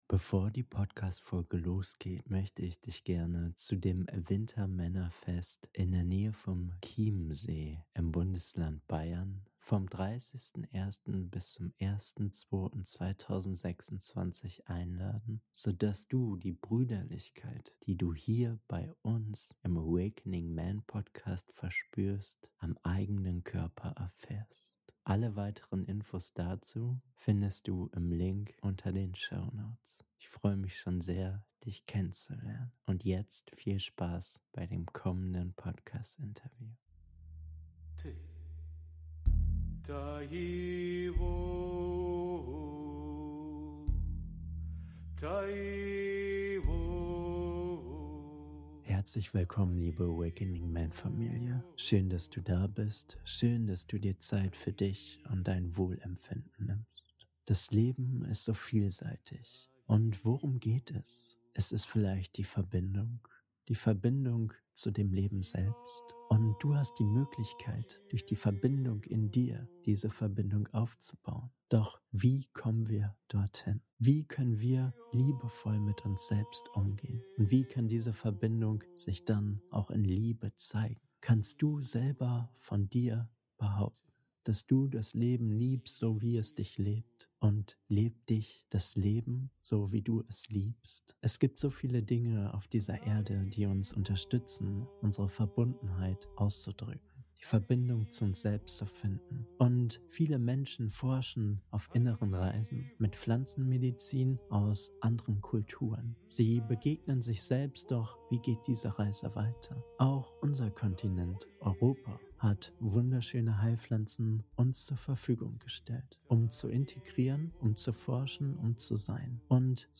Die heilige Sprache der Zauberpilze - Interview